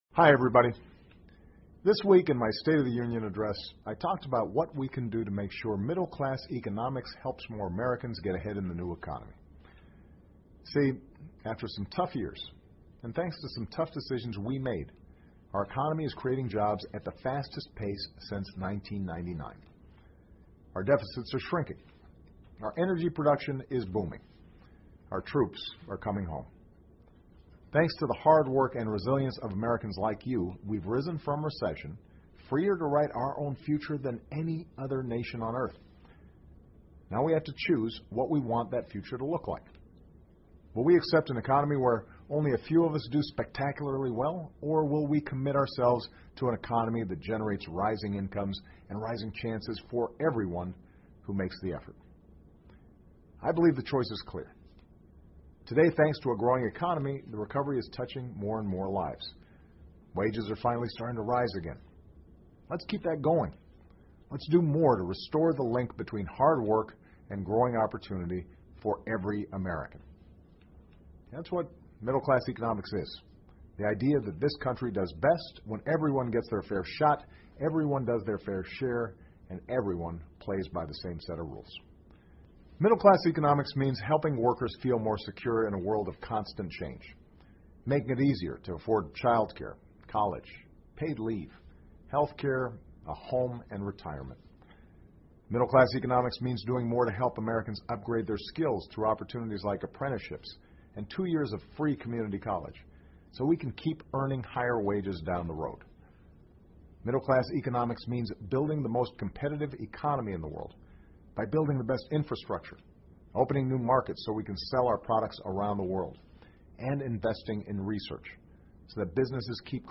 奥巴马每周电视讲话:总统呼吁拉中产阶级一把 听力文件下载—在线英语听力室